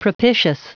Prononciation du mot propitious en anglais (fichier audio)
Prononciation du mot : propitious